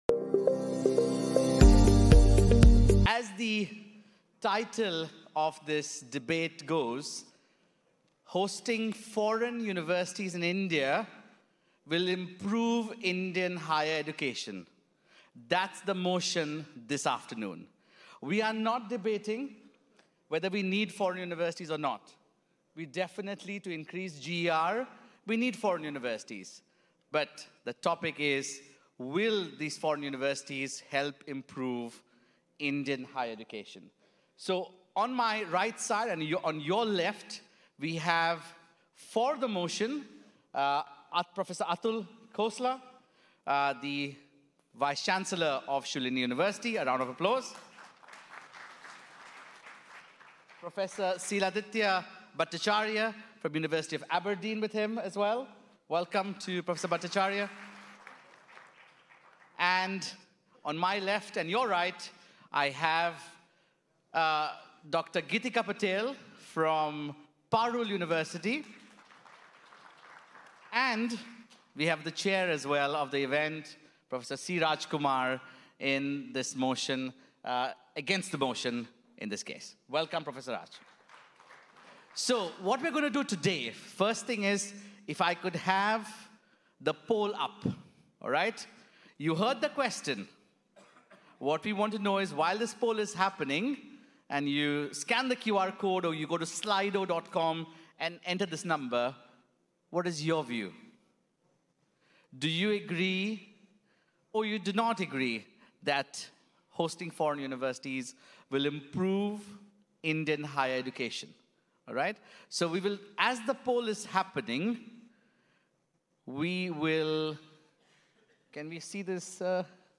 India_Summit_Debate.mp3